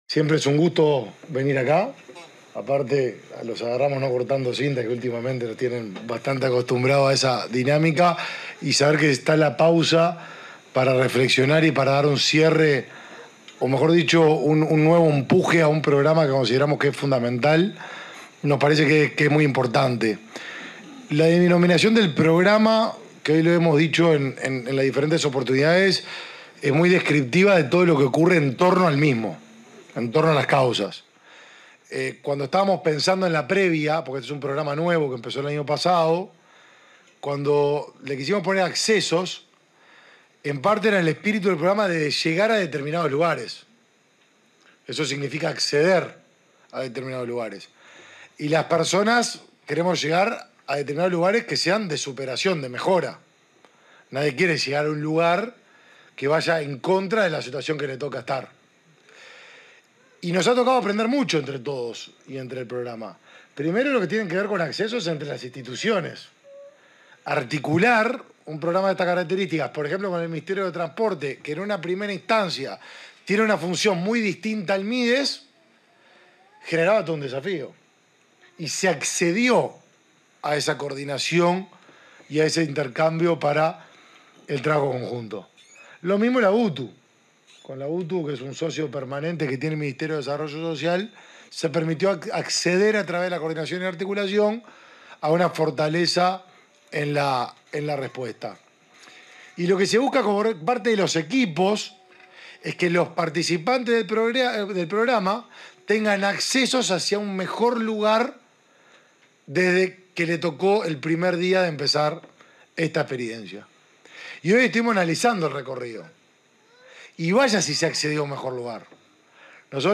Palabras del ministro de Desarrollo Social, Martín Lema
En el marco del cierre del programa Accesos edición 2023, se expresó el ministro de Desarrollo Social, Martín Lema.